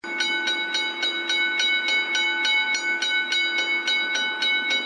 Train bell sound ringtone free download
Sound Effects